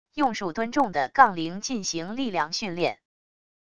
用数吨重的杠铃进行力量训练wav音频